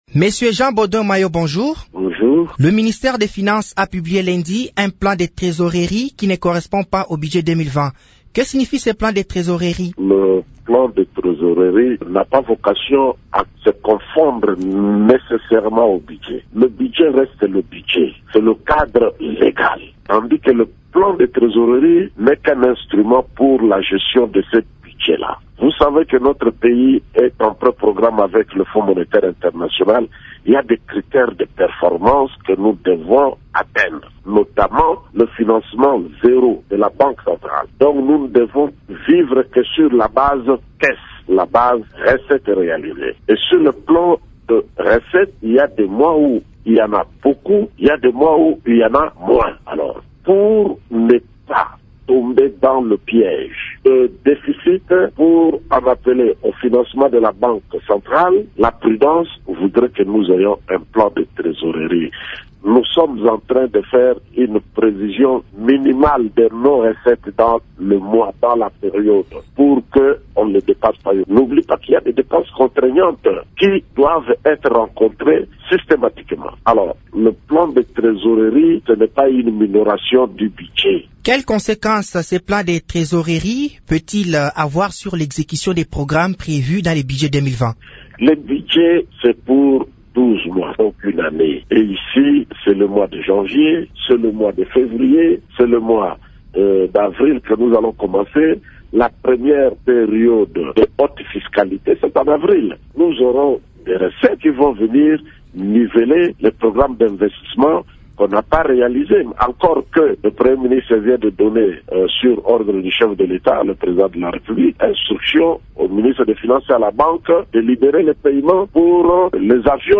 Le ministre du Budget fait remarquer que cette publication ne revoie pas à la baisse le budget 2020. Interview.